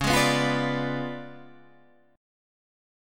C#7b9 chord